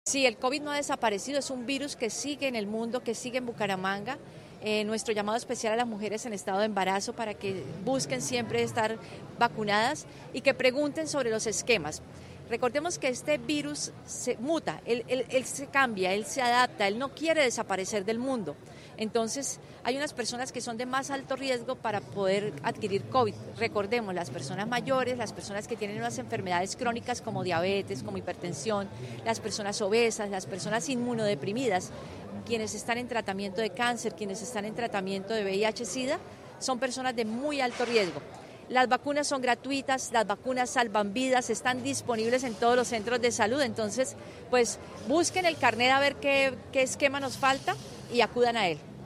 Claudia Amaya, secretaria de salud de Bucaramanga